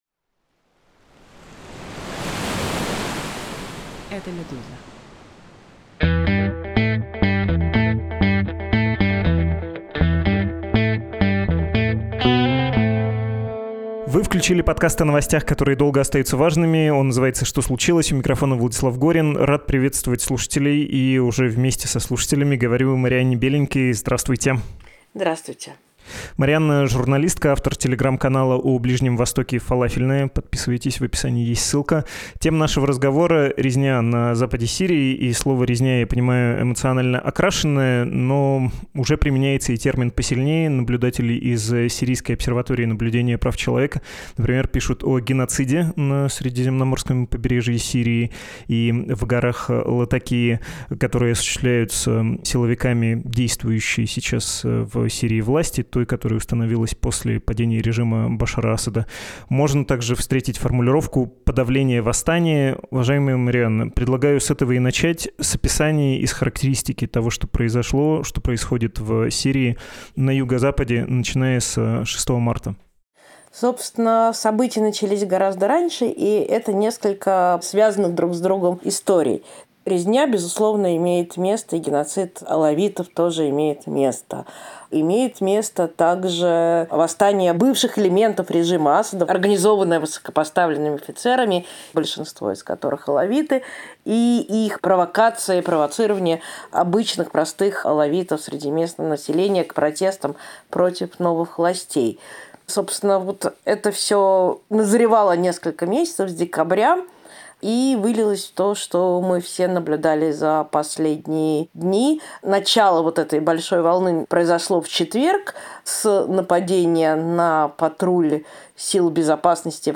«Что случилось» — новостной подкаст «Медузы».